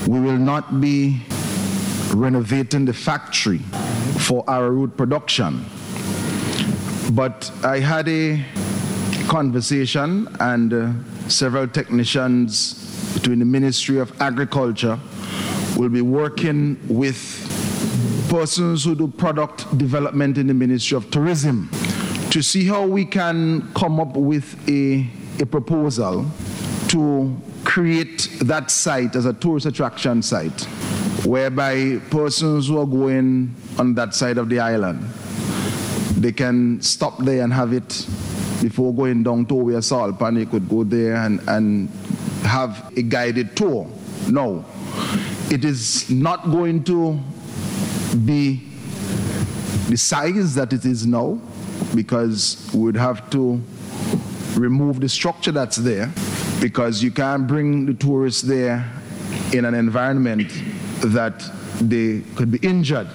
Minister Caesar made the disclosure, as he responded to a question in Parliament on Tuesday from Opposition Senator Hon. Shivern John.